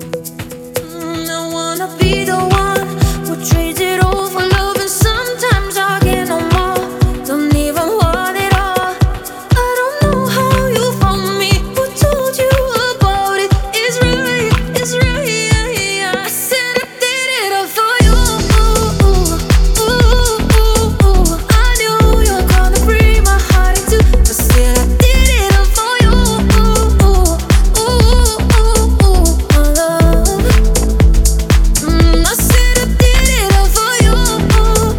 Жанр: Танцевальная музыка / Украинские